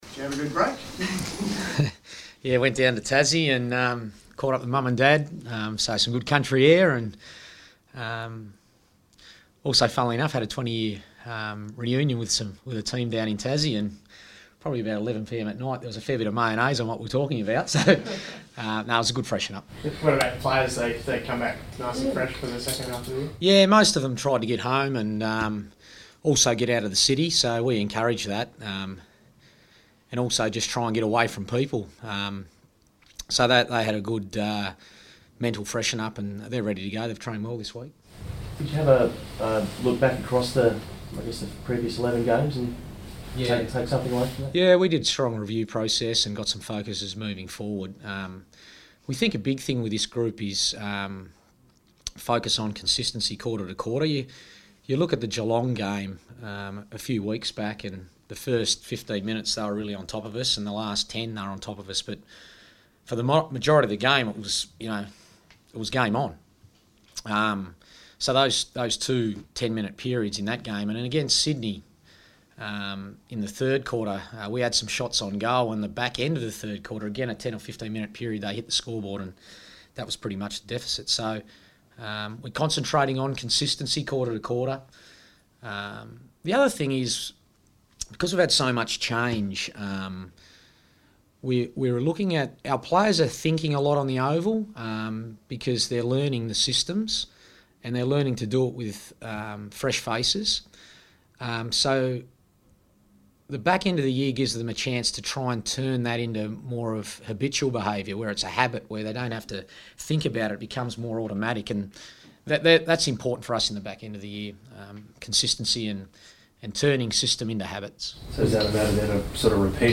press conference
fronts the media at Ikon Park ahead of the Blues' clash against Fremantle at Etihad Stadium.